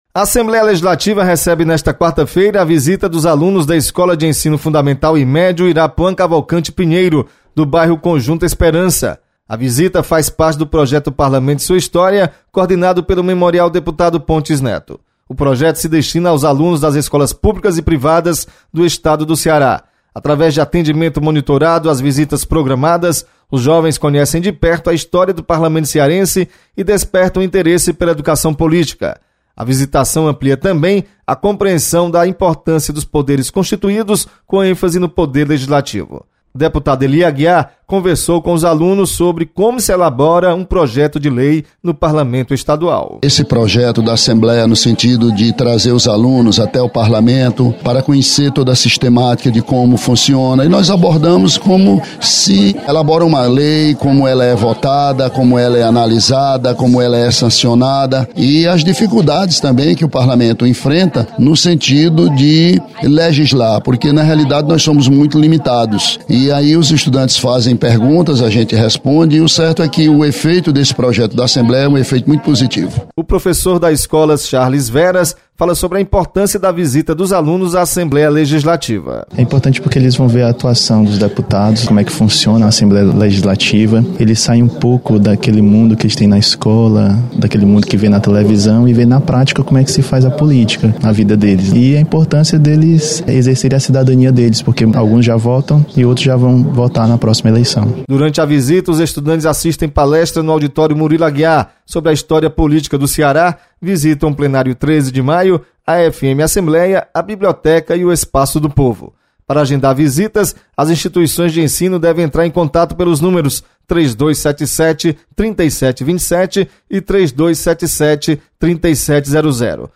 Memorial Pontes Neto recebe alunos dentro da programação do Projeto O Parlamento e Sua História. Repórter